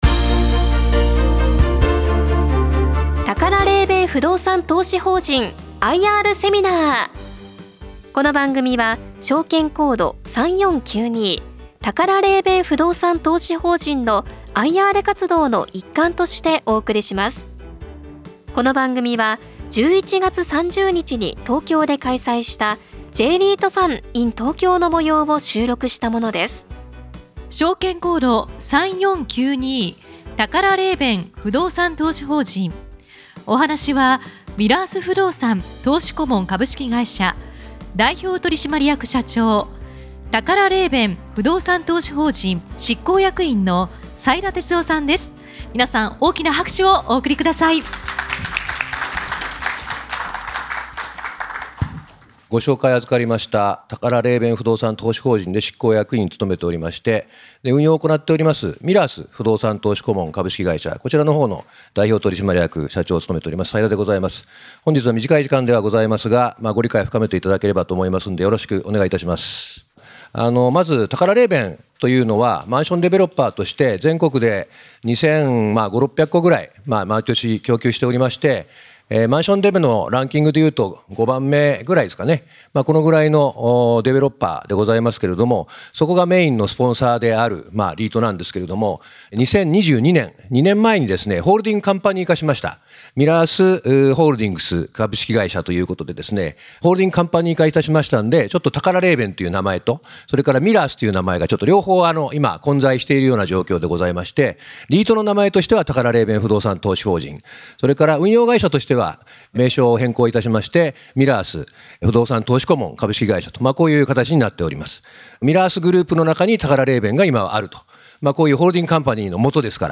この番組は2024年11月30日に東京で開催した「J-REITファン」の模様をダイジェストでお送りいたします。